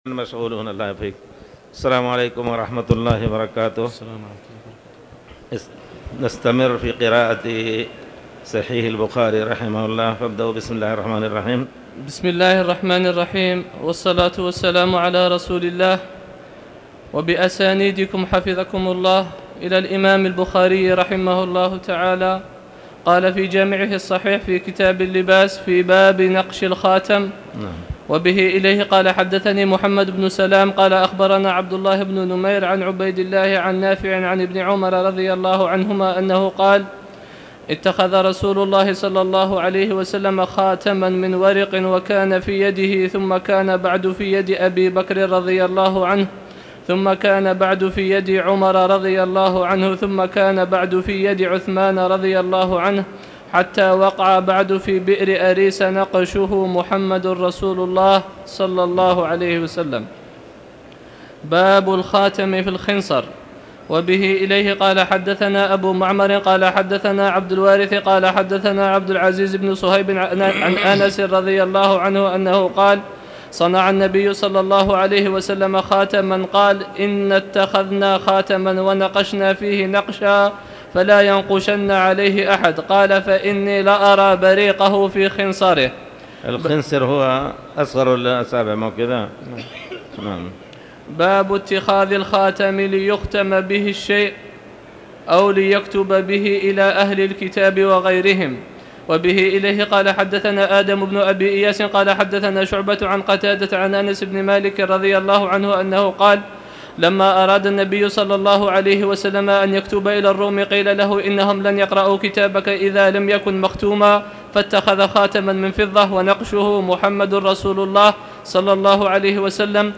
تاريخ النشر ٢ ربيع الثاني ١٤٣٨ هـ المكان: المسجد الحرام الشيخ